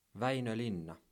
Väinö Linna (pronounced [ˈʋæi̯nø ˈlinːɑ]